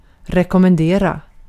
Ääntäminen
IPA: /ˌre.kom.menˈdeː ra/